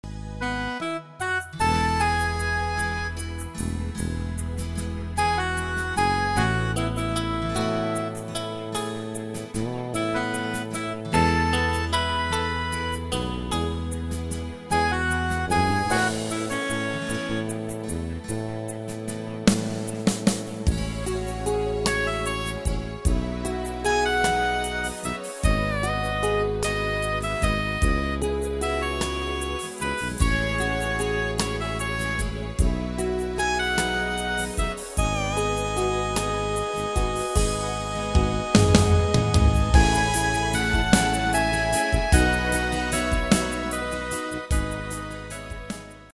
Tempo: 151 BPM.
MP3 s ML DEMO 30s (0.5 MB)zdarma